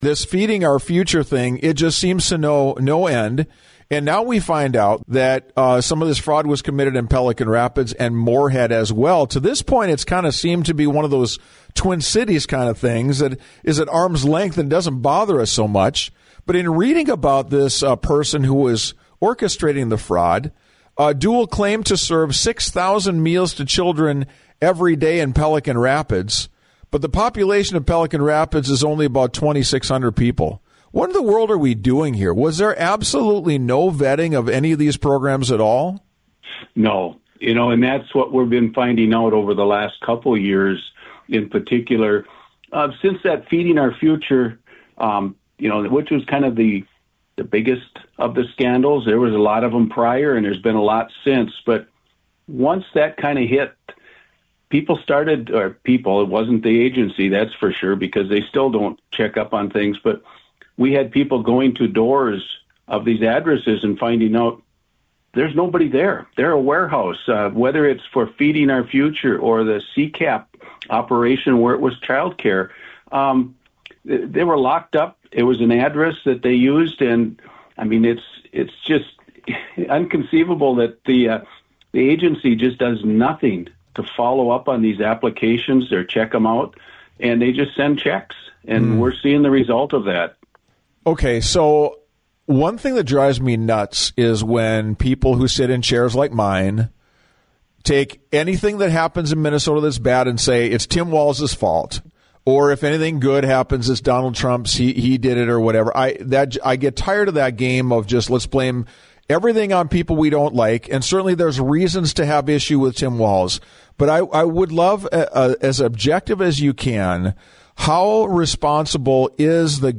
Republican State Senator Paul Utke of Park Rapids